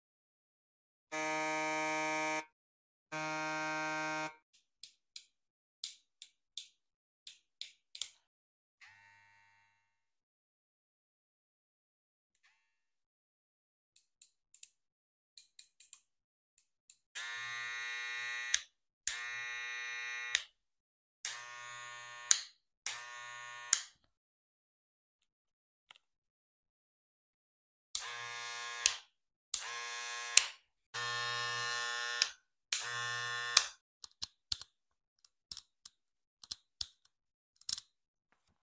Asmr clipper sounds of 4 sound effects free download
Asmr clipper sounds of 4 diferrent clippers enjoy